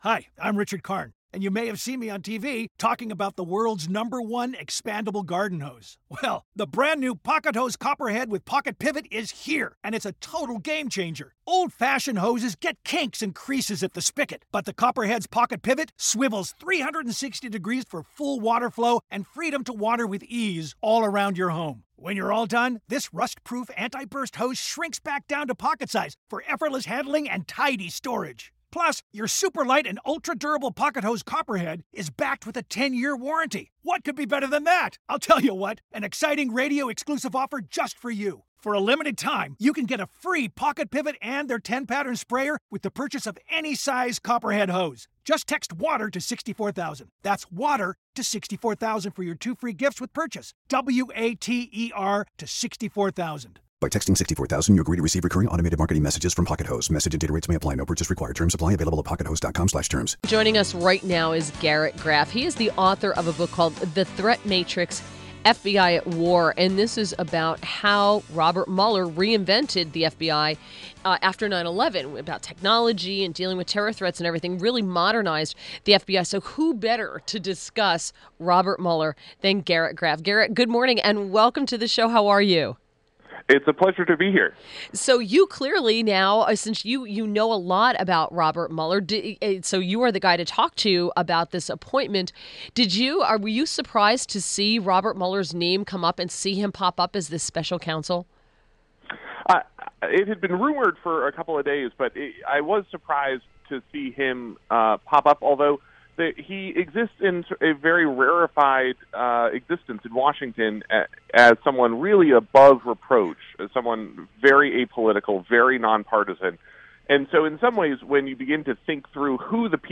WMAL Interview - Garrett Graff 05.17.18